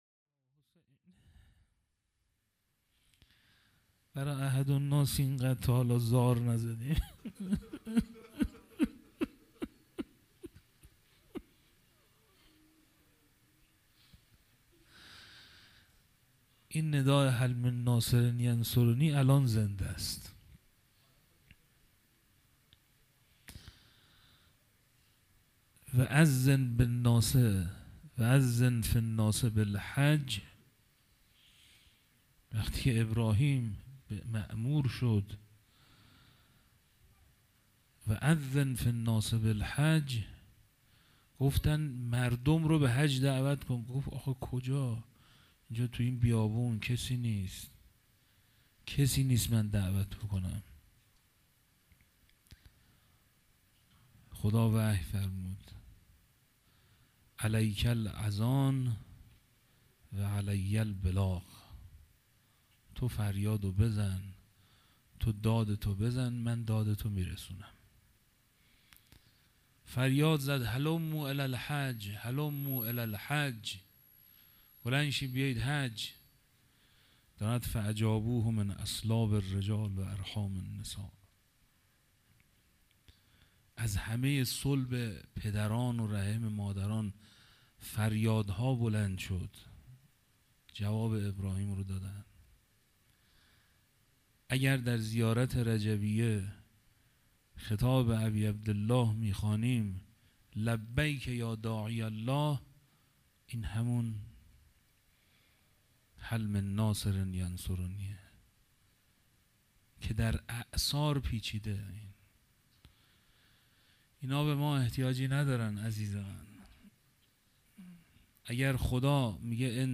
شب عاشورا محرم 97 - هیئت مکتب المهدی عجل الله تعالی فرجه